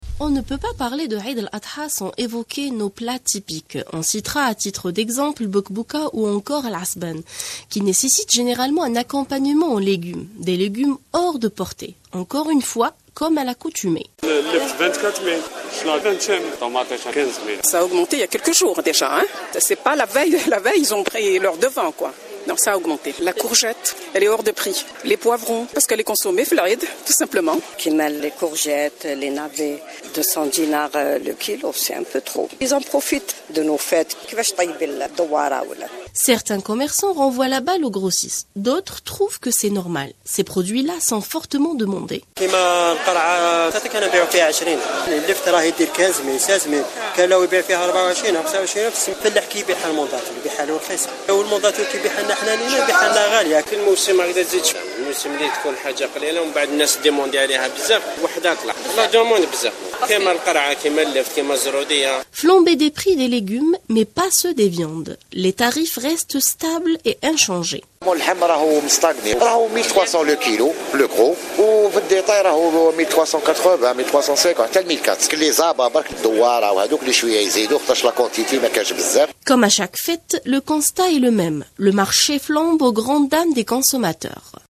Les prix des fruits et légumes explosent - Reportage à Sidi bel Abbes